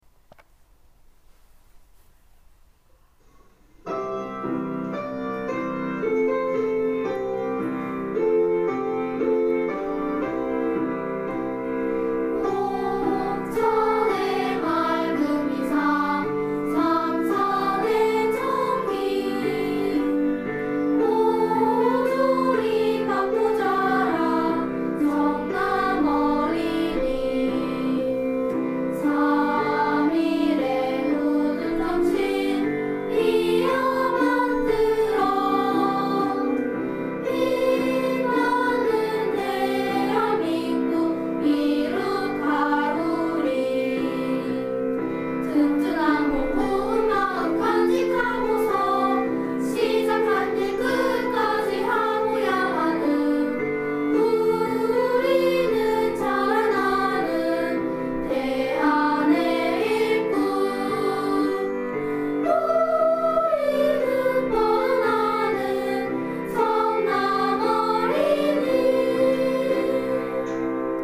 교가